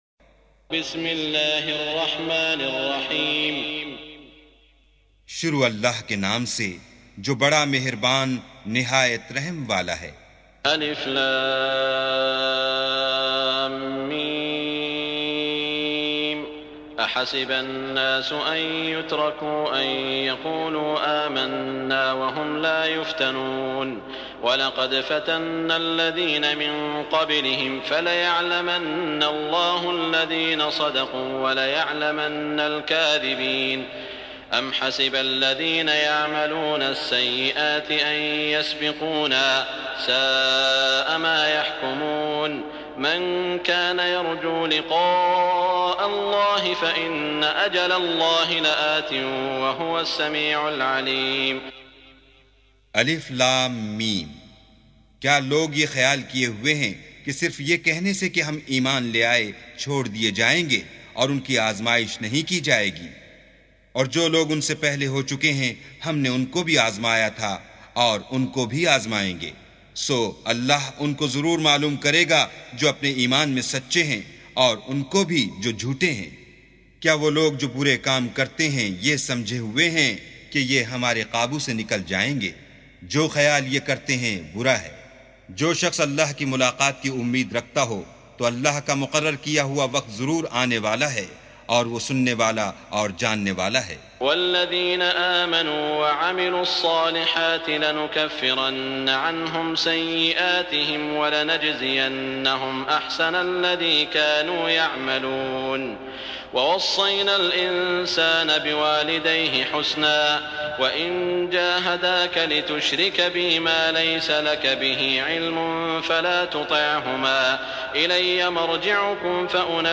سُورَةُ العَنكَبُوتِ بصوت الشيخ السديس والشريم مترجم إلى الاردو